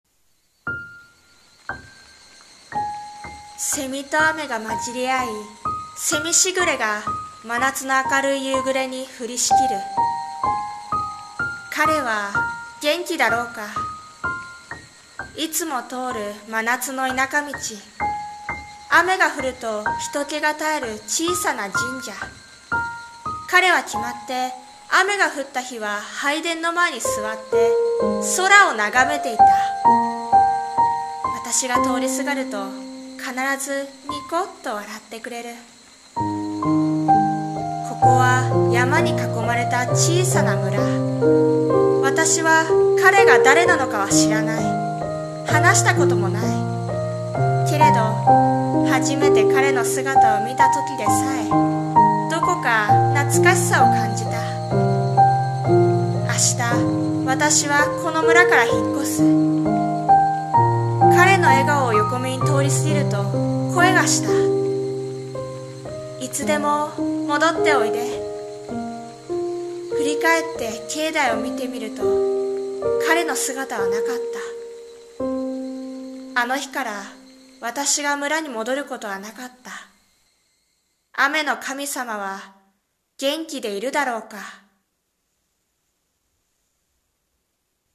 さんの投稿した曲一覧 を表示 雨の神様 【和風】【朗読】【台本】【雨企画】